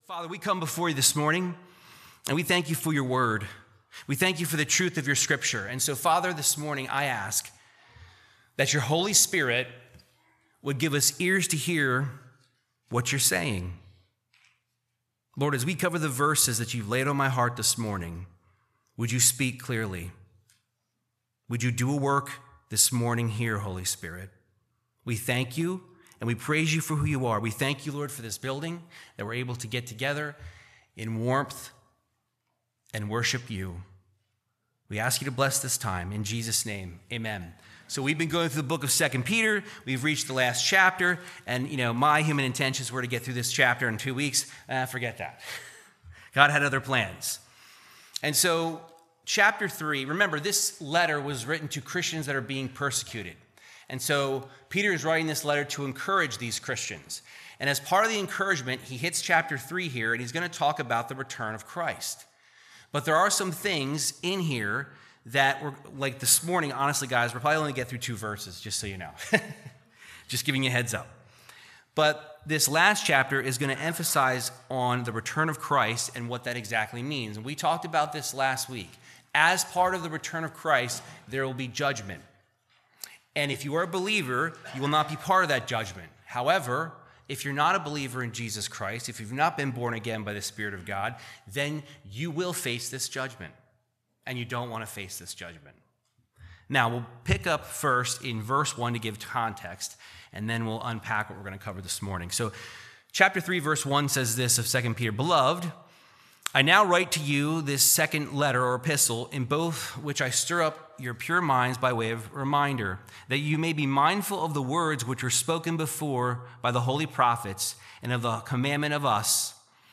Verse by verse Teaching of 2 Peter 3:8-9